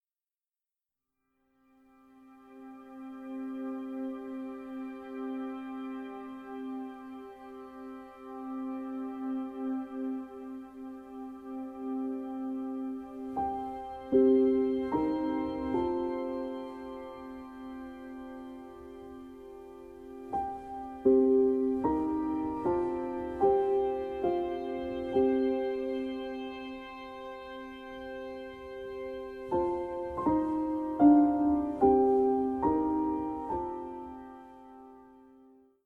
Tónica Do